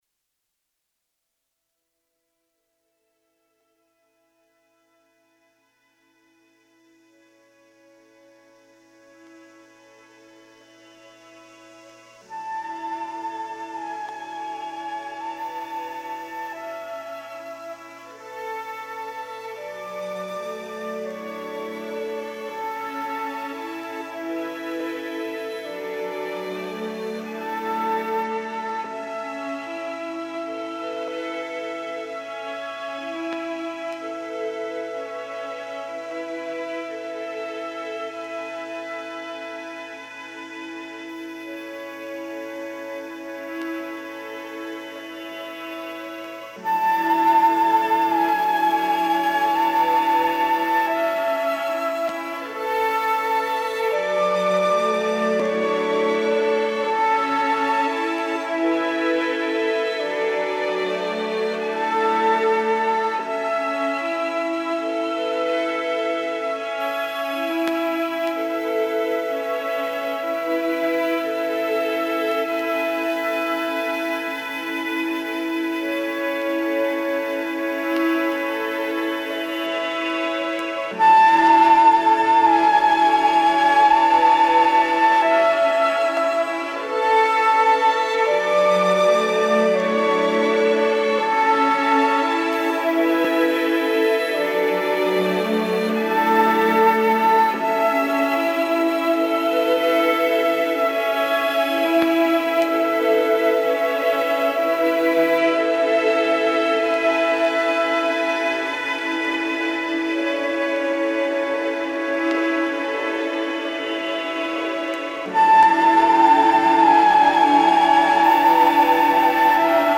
at Winona State University as part of the iDMAa conference.